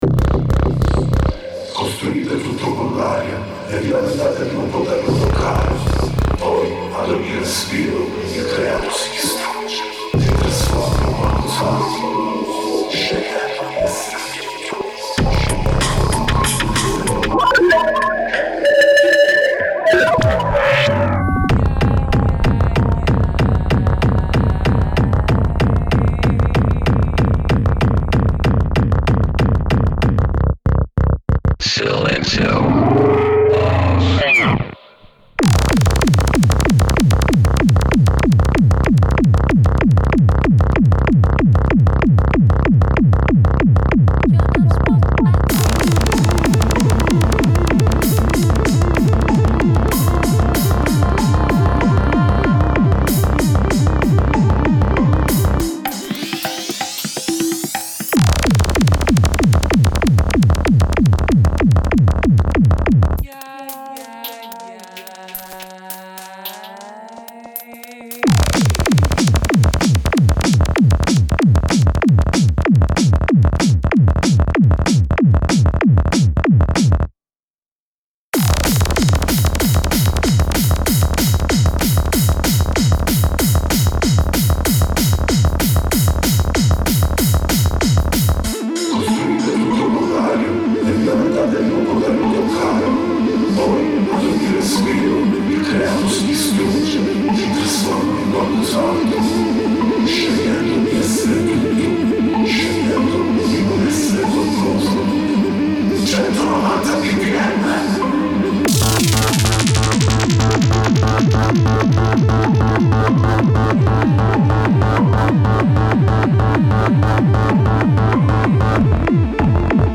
Hardtek , tekno